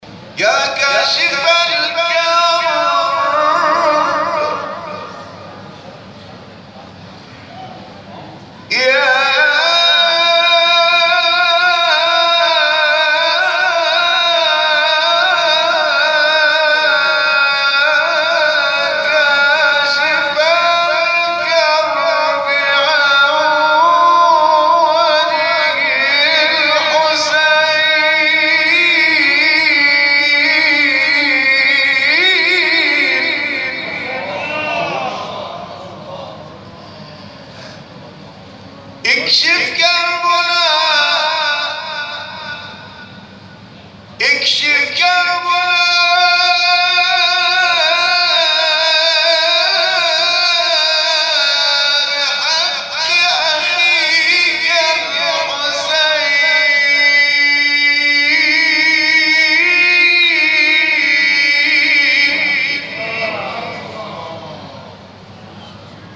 گروه فعالیت‌های قرآنی: محفل انس با قرآن کریم، شب گذشته، 25 خردادماه به مناسبت ماه مبارک رمضان در مسجد شهدای تهران برگزار شد.
ابتهال